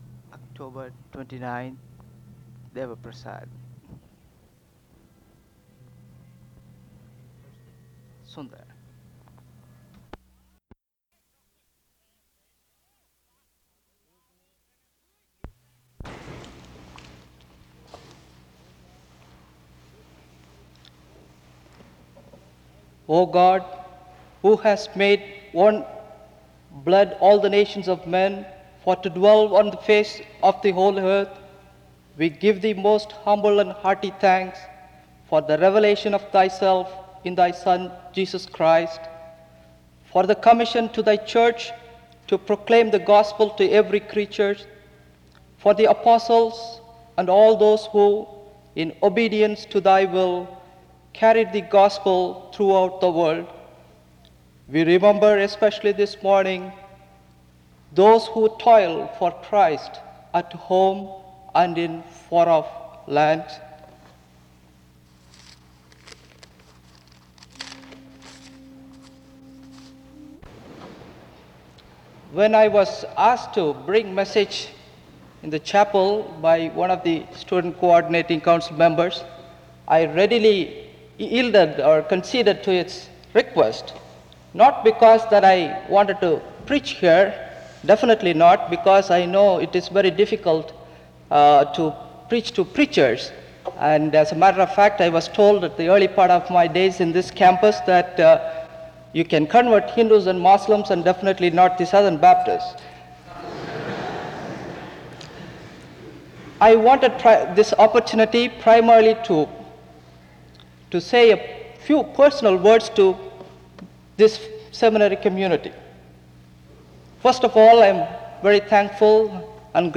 SEBTS Chapel
There is closing music from 21:30-21:54. This service was organized by the Student Coordinating Council.